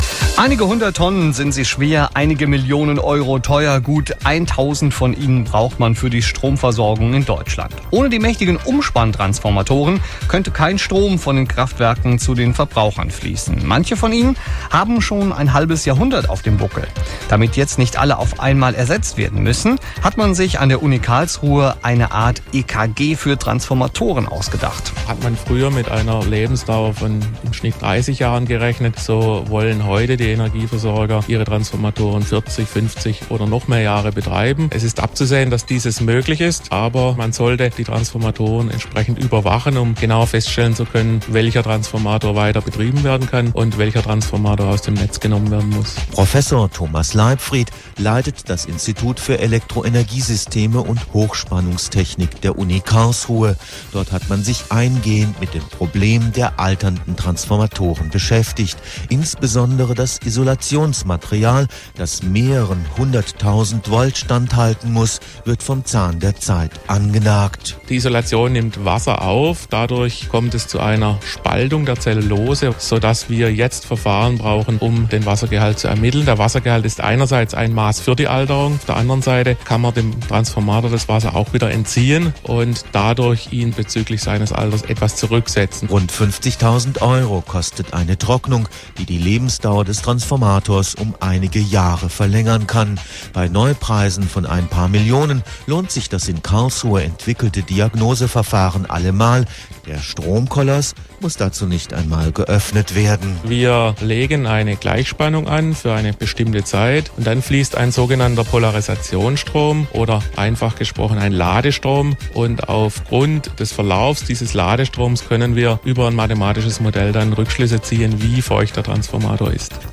Interviewter